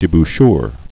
(dĭ-bshr)